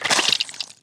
deathSound.wav